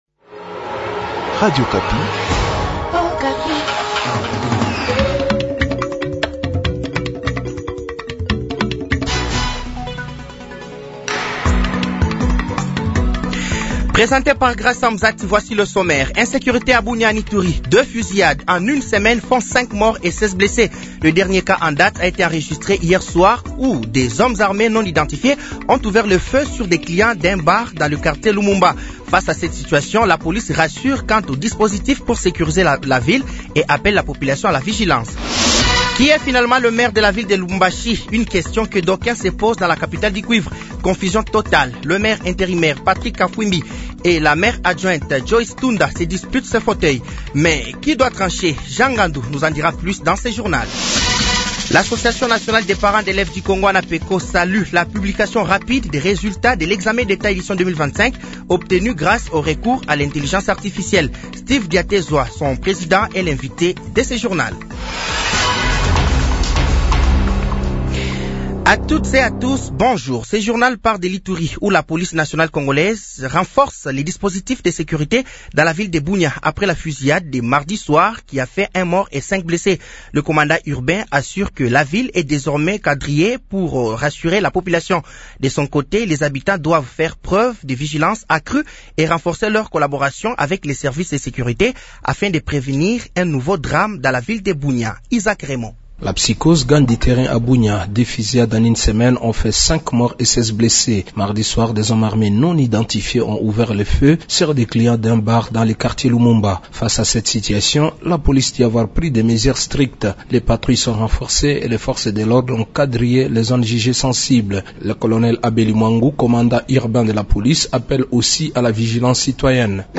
Journal français de 12h de ce mercredi 06 août 2025